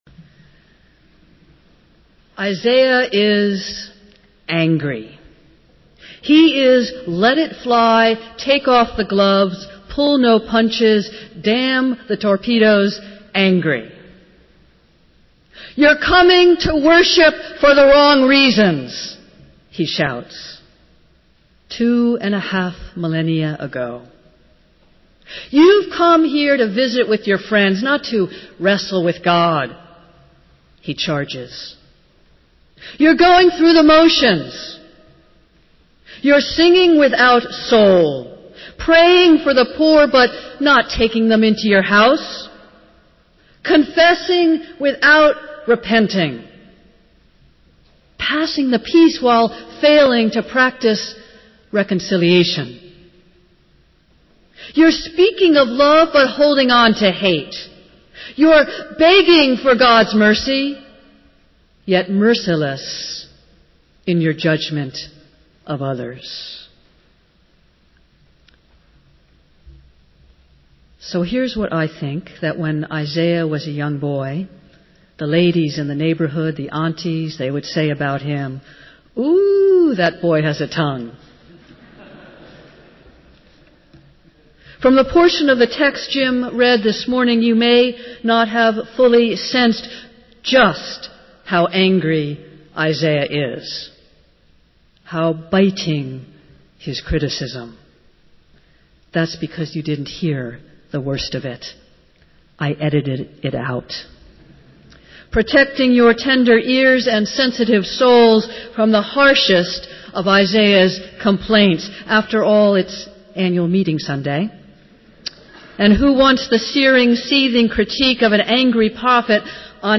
Festival Worship - Annual Meeting Sunday